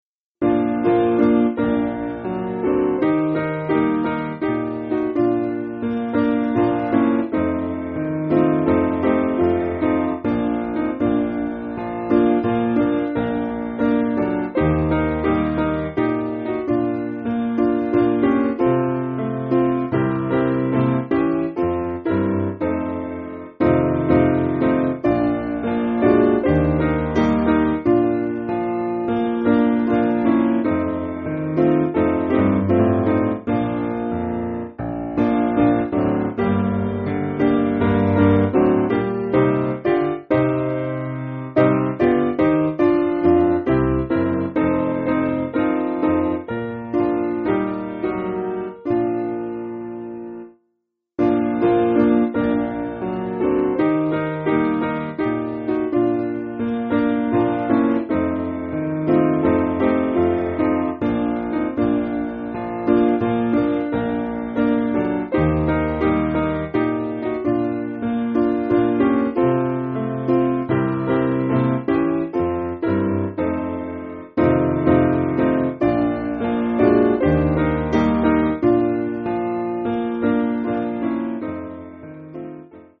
Simple Piano
(CM)   4/Bb